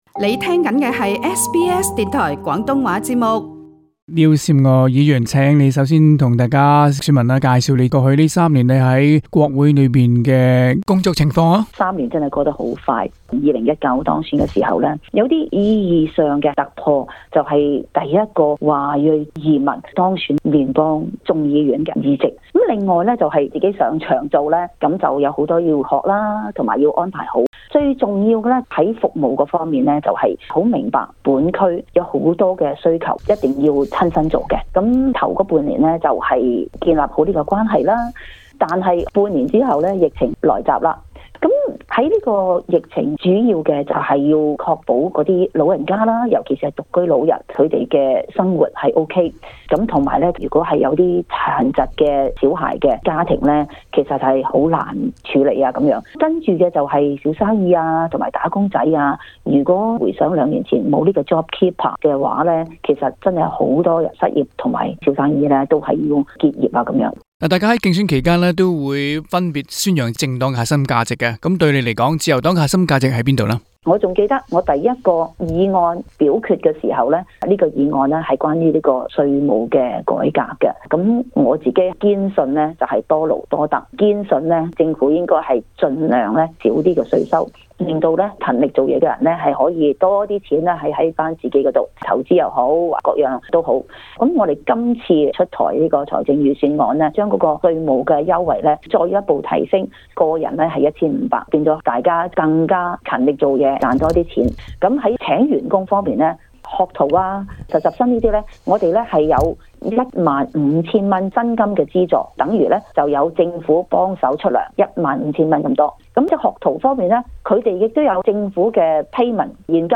【聯邦大選】訪問 Chisholm 自由黨候選人廖嬋娥議員